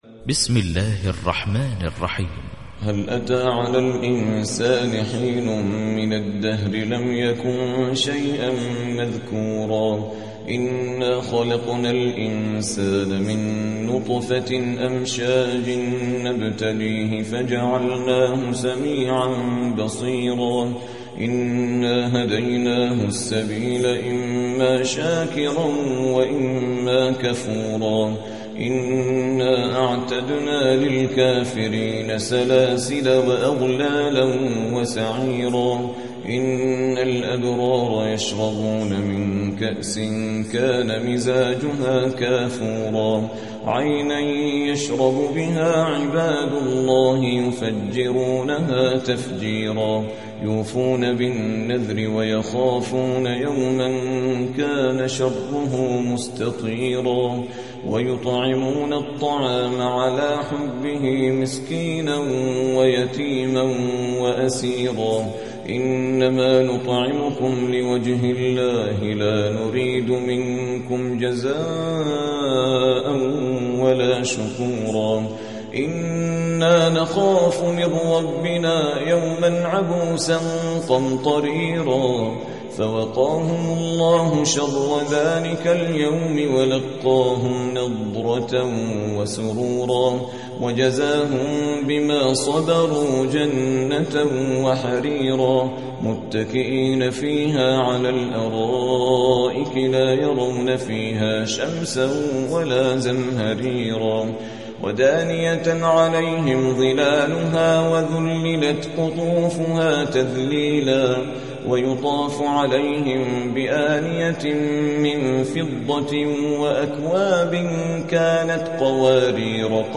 76. سورة الإنسان / القارئ